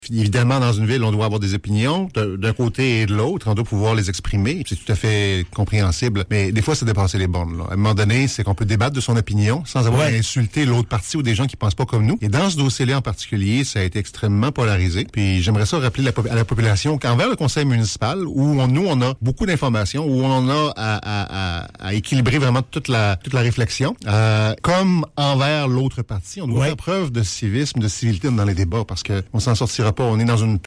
Monsieur Caron invite les mécontents face au projet Le Phare, qui se sont manifesté sur les réseaux sociaux et à l’hôtel de ville, à utiliser un ton plus respectueux :